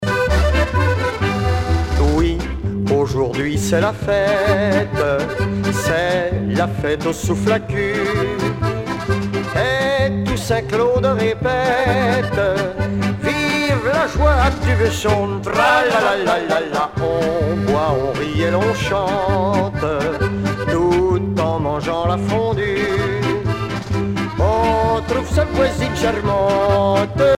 danse : marche
Genre strophique
Pièce musicale éditée